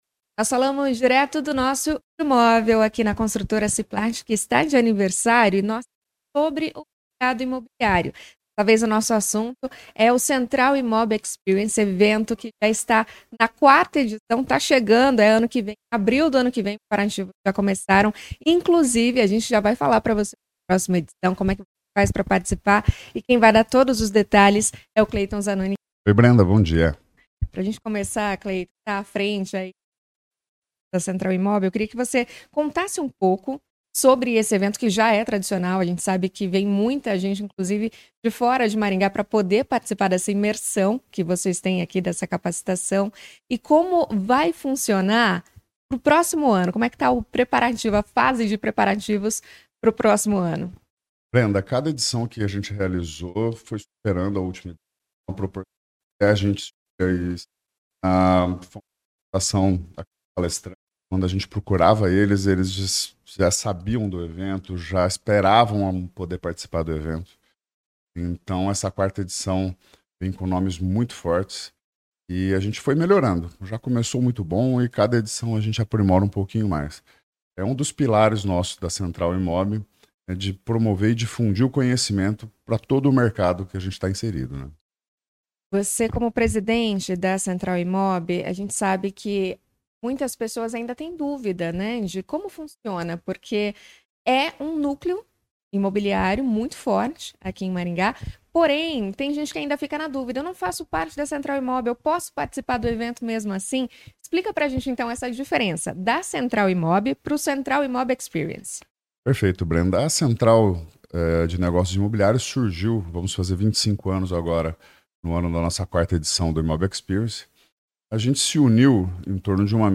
A entrevista foi realizada no estúdio móvel instalado na sede da Ciplart Construtora, dentro do projeto CBN nas Empresas.